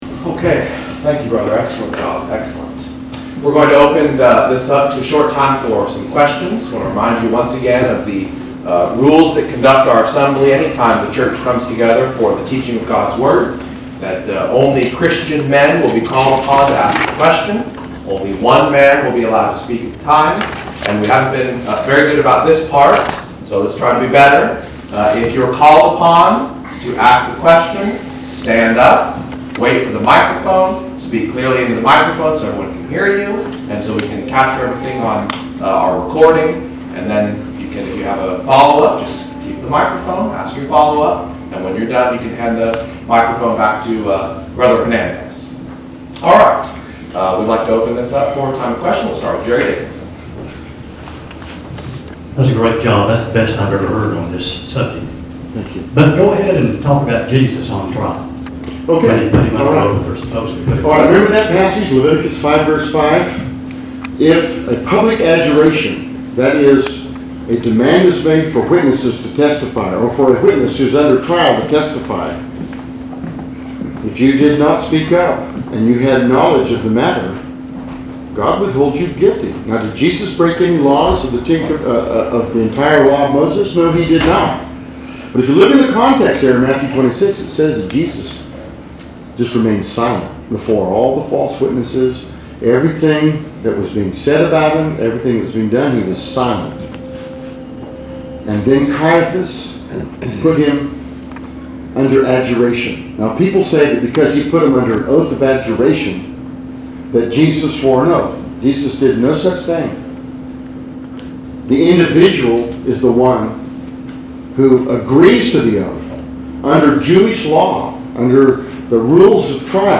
question and answer session